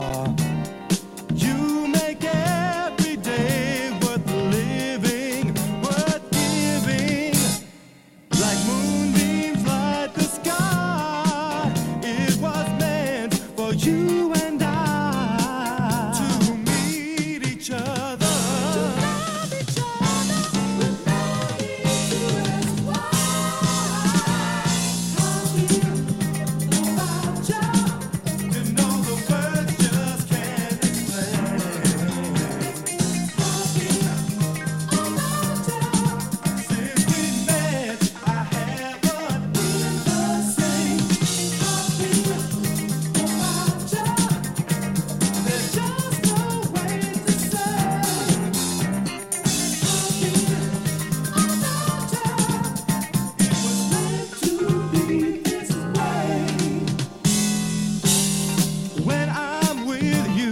guitar
drums.